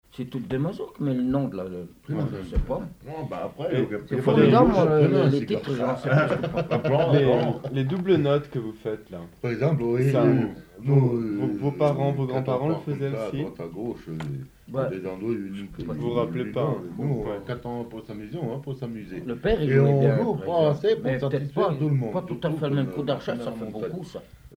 Commentaire
Catégorie Témoignage